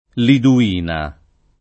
vai all'elenco alfabetico delle voci ingrandisci il carattere 100% rimpicciolisci il carattere stampa invia tramite posta elettronica codividi su Facebook Liduina [ lidu- & na ] (meno com. Lidovina [ lidov & na ]) pers. f.